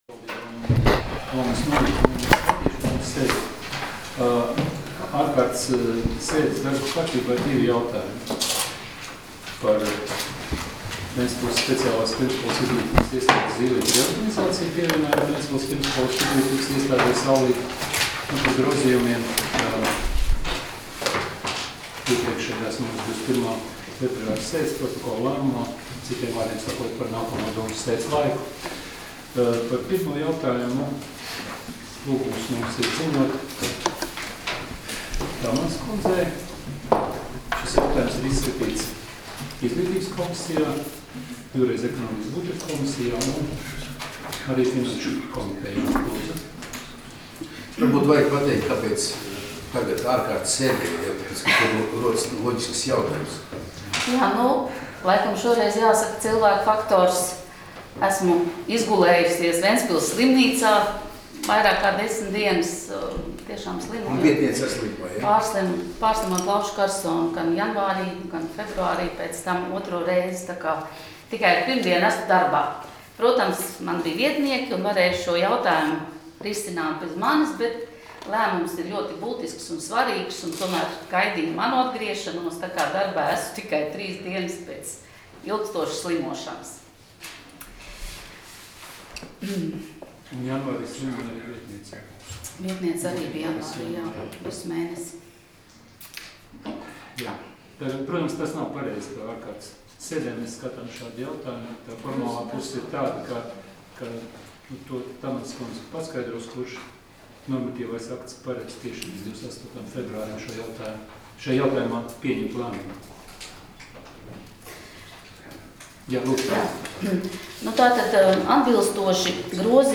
Domes sēdes 28.02.2019. audioieraksts